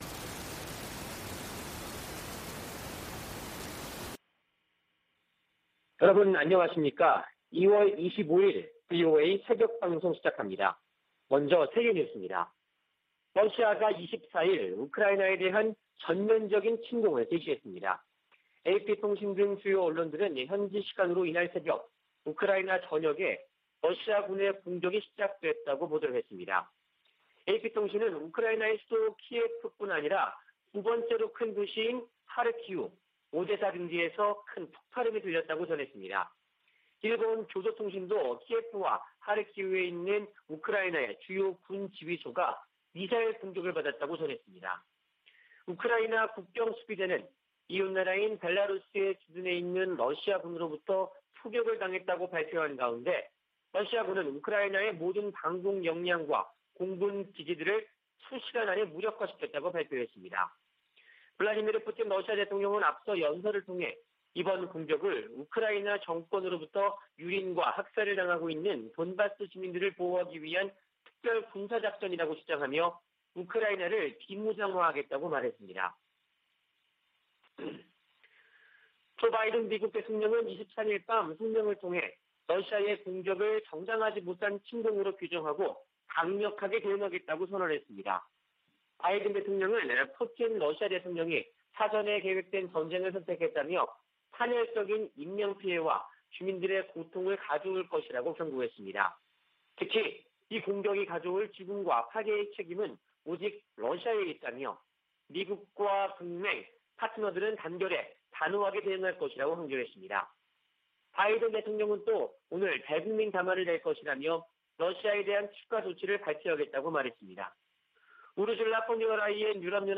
VOA 한국어 '출발 뉴스 쇼', 2021년 2월 25일 방송입니다. 러시아의 우크라이나 침공으로 미-러 갈등이 격화되고 있는 가운데 북한의 외교 셈법이 복잡해졌다는 분석이 나오고 있습니다. 미 국방부는 우크라이나에 대한 한국의 지지 성명에 주목했다고 밝혔습니다. 미국의 전통적 대북 접근법으로는 북한 문제를 해결하는 데 한계가 있으며, 대통령의 리더십이 중요하다는 보고서가 나왔습니다.